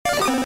Cri de Grodoudou K.O. dans Pokémon Diamant et Perle.